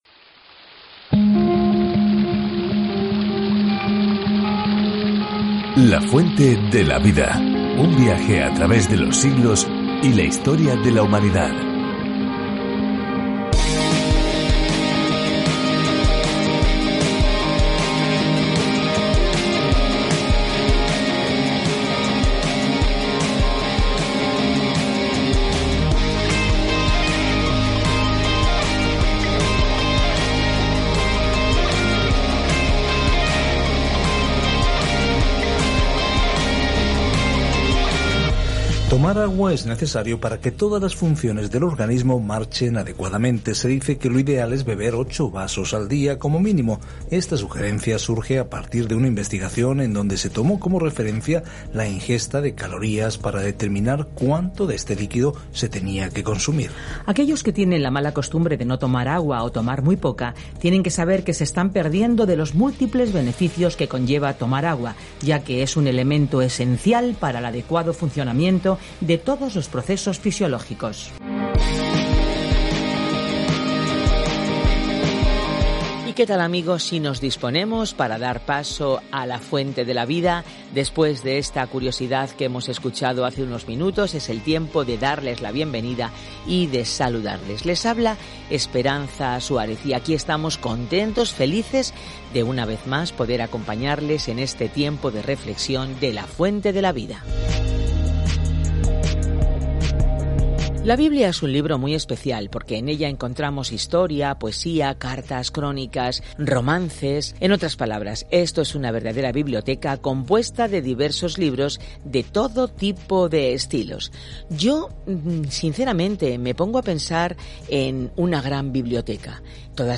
Escritura GÉNESIS 41:1-45 Día 44 Iniciar plan Día 46 Acerca de este Plan Aquí es donde comienza todo: el universo, el sol y la luna, las personas, las relaciones, el pecado, todo. Viaja diariamente a través de Génesis mientras escuchas el estudio de audio y lees versículos seleccionados de la palabra de Dios.